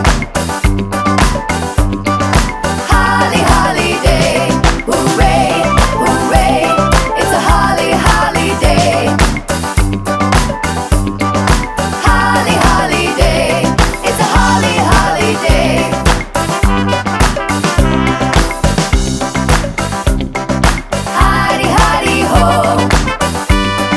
no Backing Vocals or Vox Percussion Disco 3:26 Buy £1.50